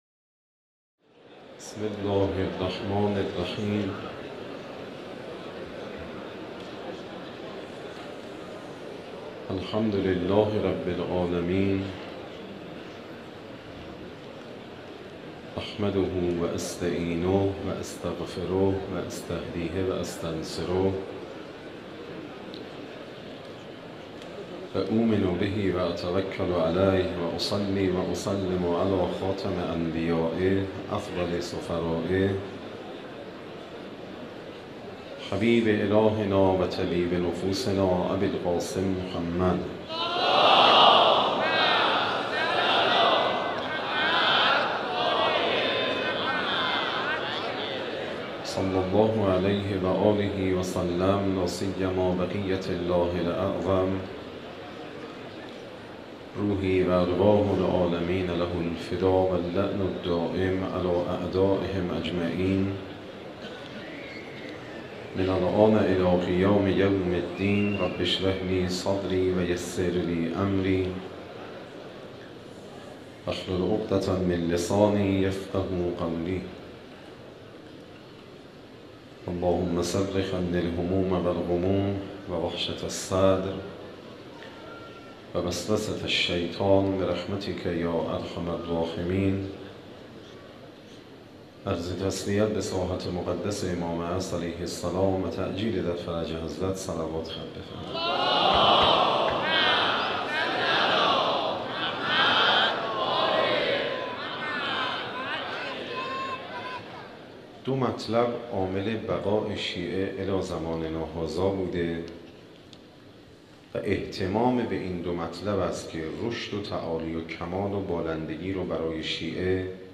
Moharrame 93, Shabe 09, Sokhanrani.mp3